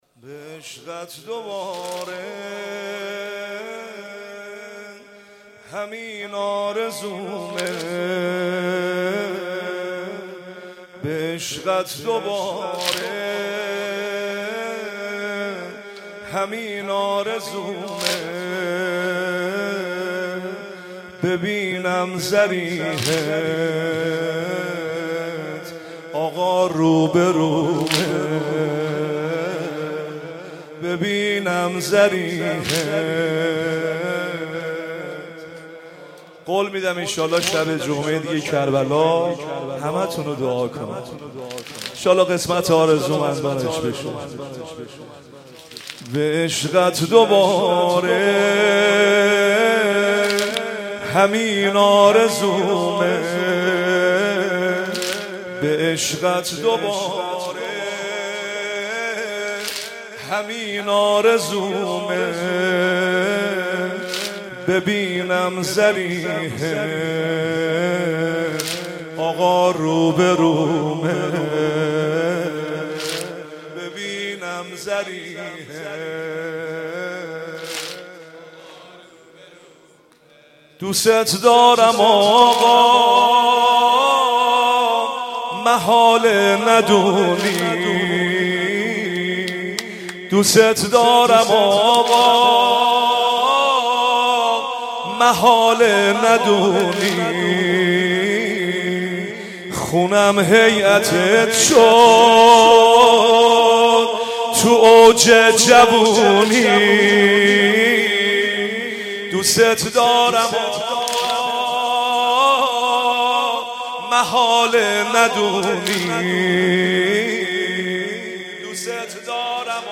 نوحه و مداحی